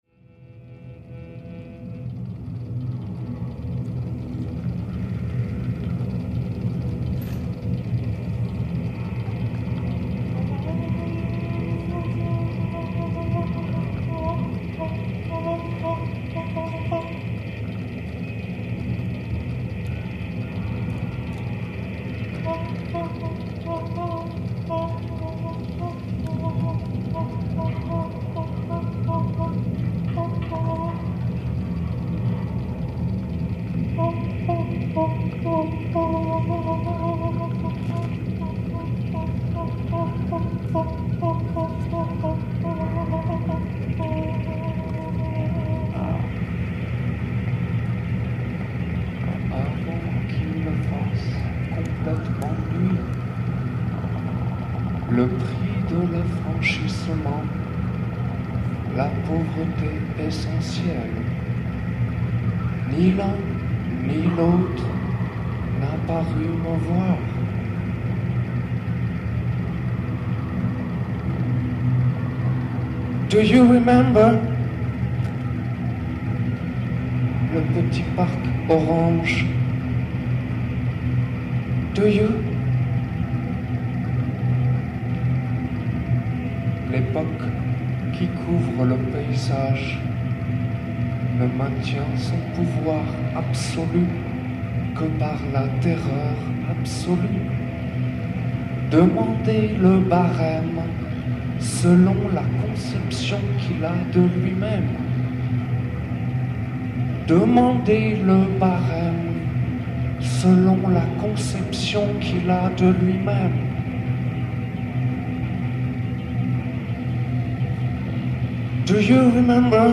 Au bout de la langue - Lectures publiques au triangle
un jeune homme clôt la soirée avec une lecture lancinante sur une bande magnétique.